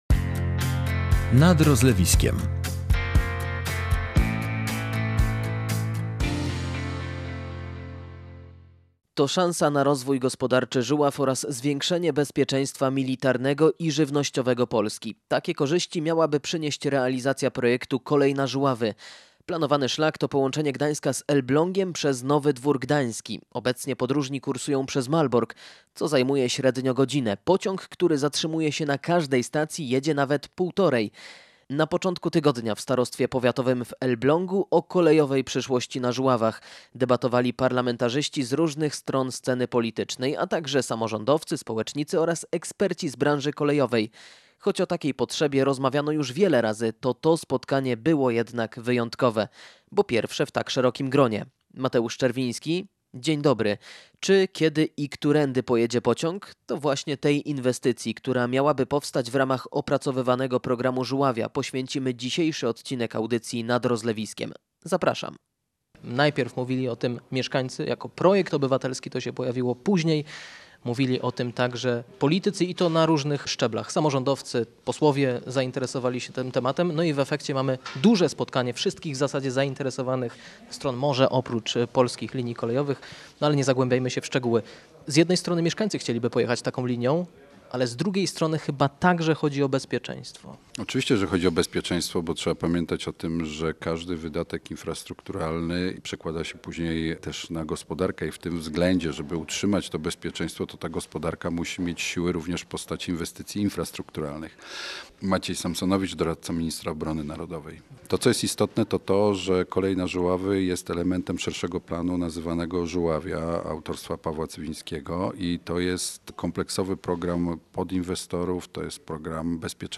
W Starostwie Powiatowym w Elblągu o kolejowej przyszłości na Żuławach debatowali parlamentarzyści z różnych stron sceny politycznej, a także samorządowcy, społecznicy oraz eksperci z branży kolejowej.